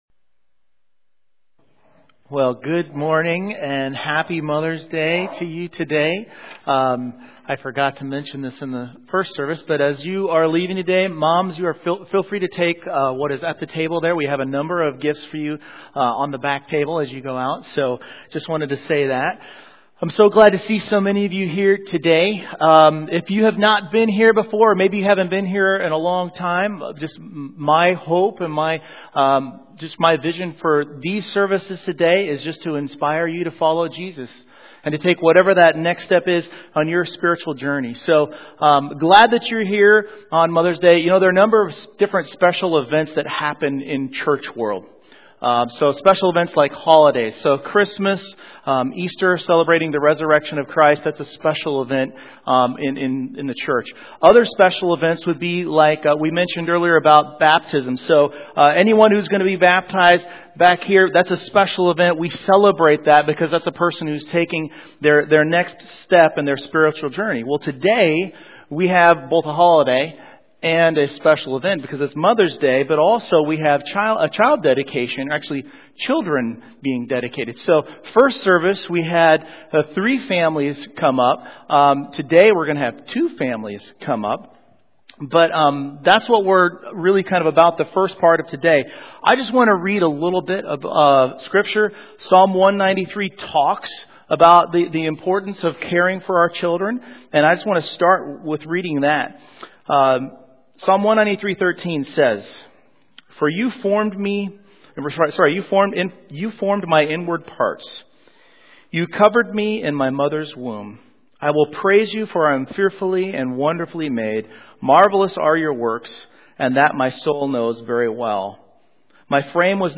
The first part of today’s message time has a Child Dedication, which ties in well with the main message (the second part).
Series: 2021 Sermons